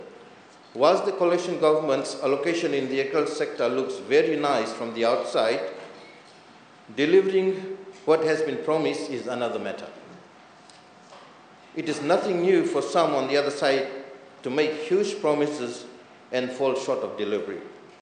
In his maiden speech this morning, Nand says he is honoured to be given another opportunity to be in parliament and to serve the public.